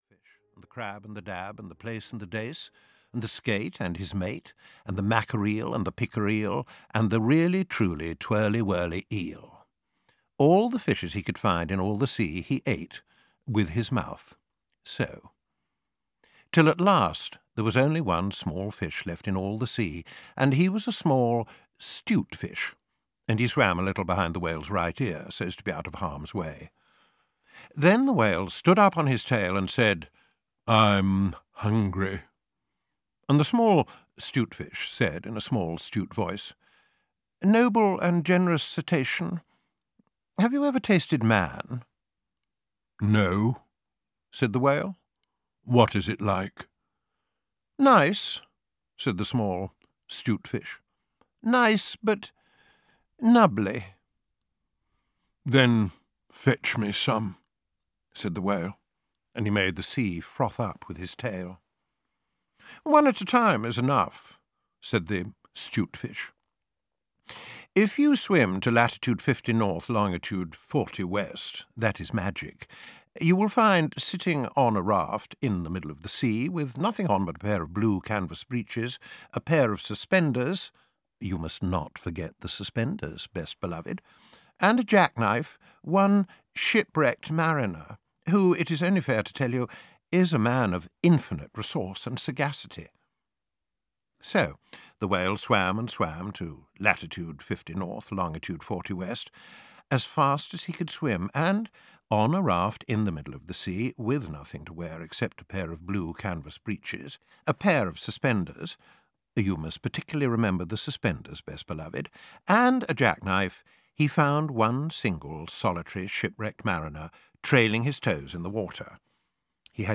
Just So Stories (EN) audiokniha
Ukázka z knihy
• InterpretGeoffrey Palmer